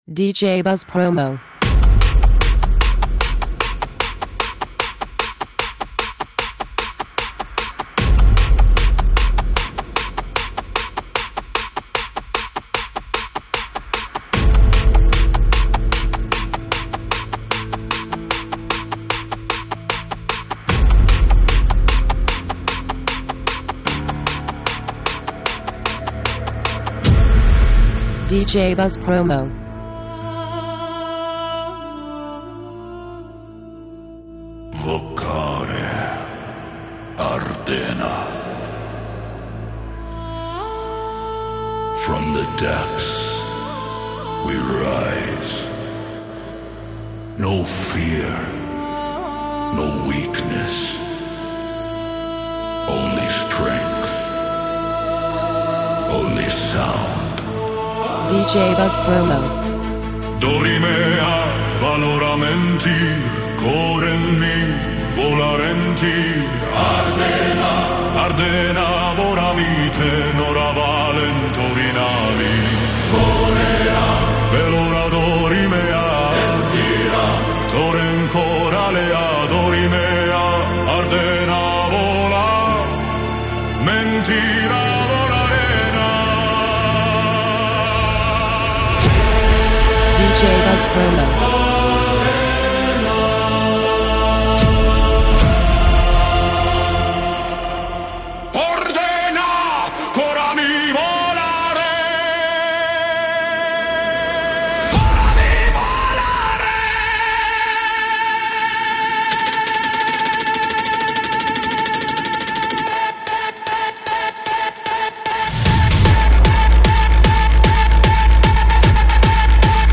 Extended
Harder. Deeper. Louder.